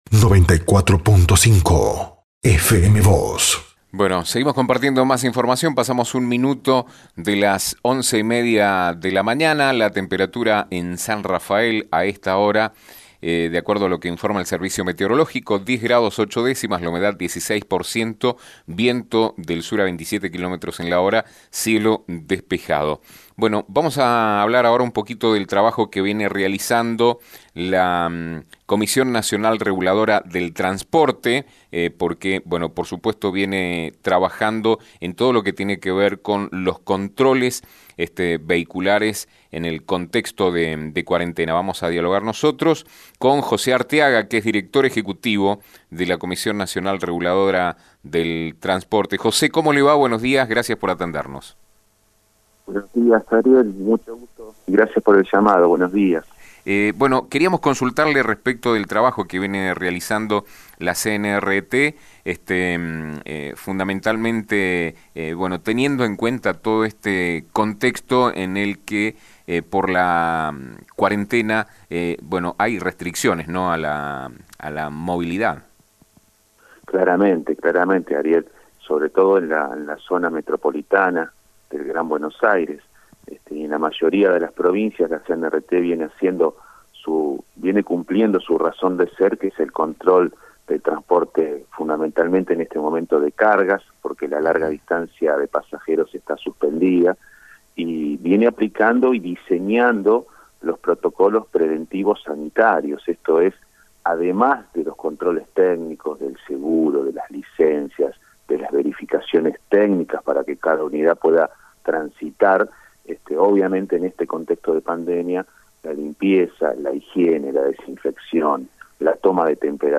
La Comisión Nacional de Regulación del Transporte (CNRT) viene realizando desde hace meses, un intenso trabajo de controles vehiculares en el contexto de cuarentena. Sobre este tema habló con FM Vos (94.5) y con Diario San Rafael, José Artiaga, director ejecutivo del organismo.